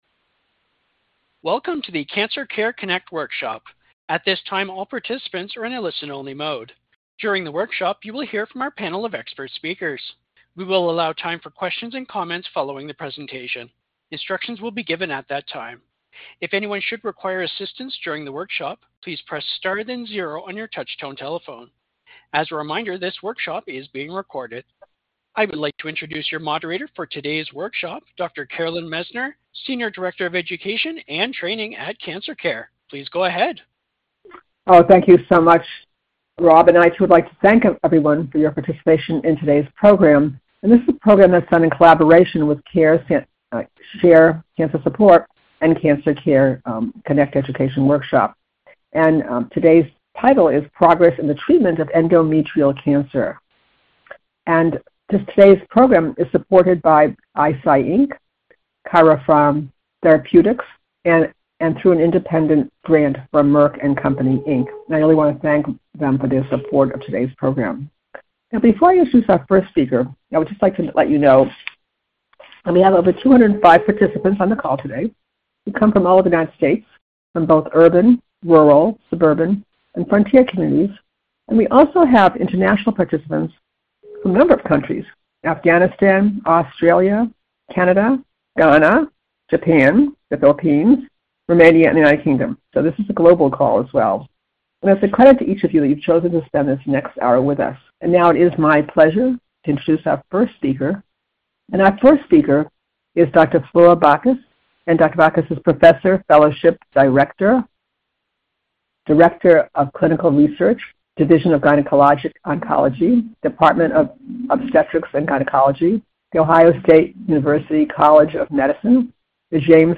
Questions for Our Panel of Experts
This workshop was originally recorded on November 19, 2024.